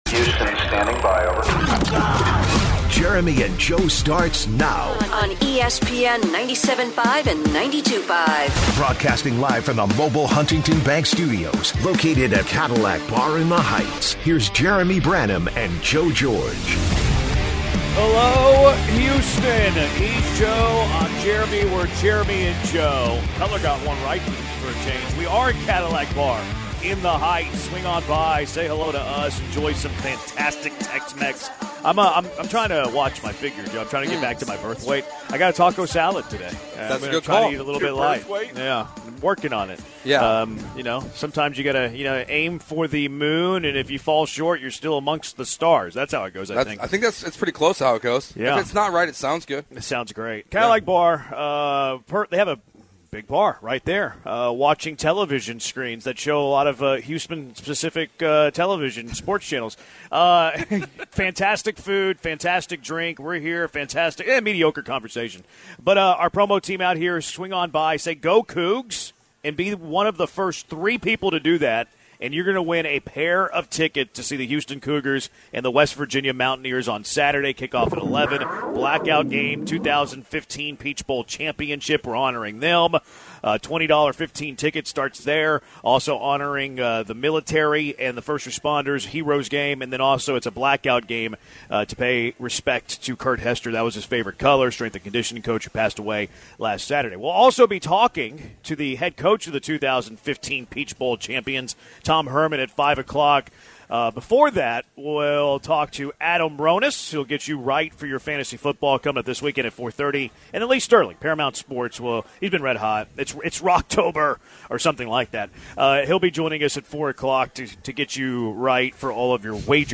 Live at the Cadillac Bar in the Heights